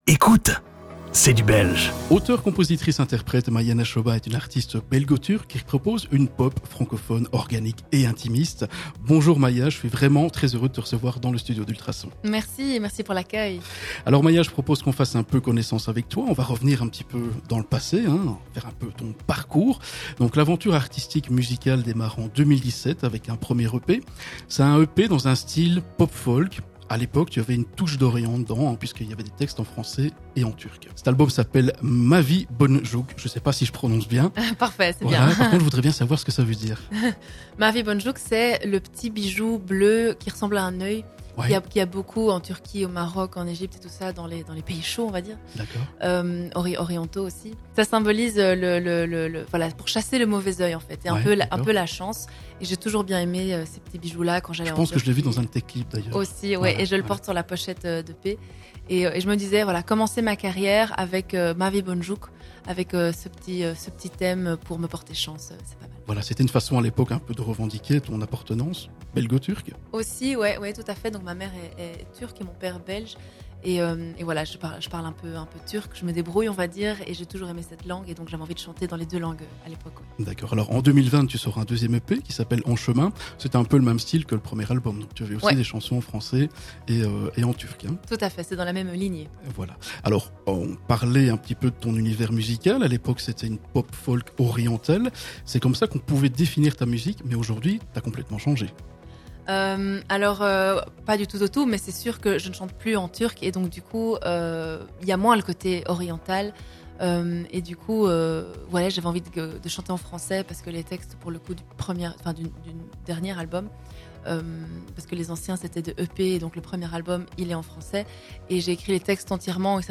une pop francophone organique, intime et sincère.
Sa musique, riche en émotions, se distingue par l’authenticité de ses textes, une voix touchante et envoûtante, des guitares acoustiques réconfortantes et des mélodies vivantes.